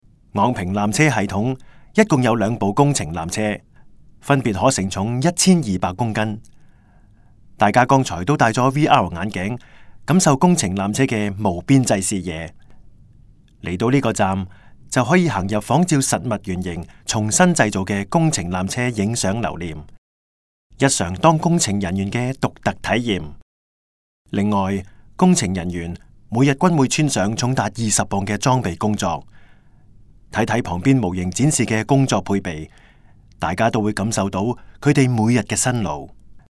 纜車探知館語音導賞 (廣東話)